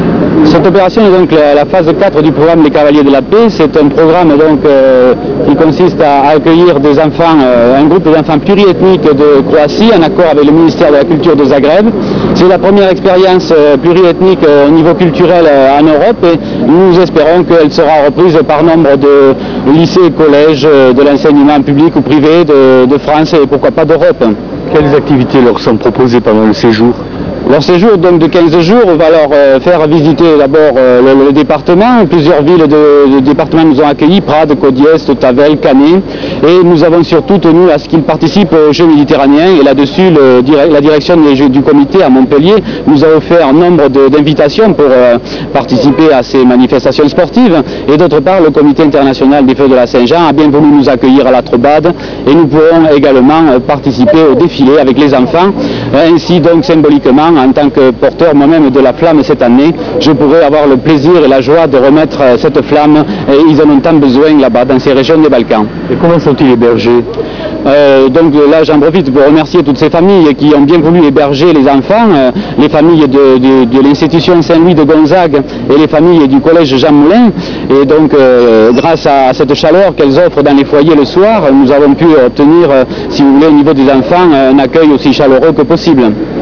Interview France 3 (930 Ko)